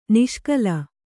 ♪ niṣkala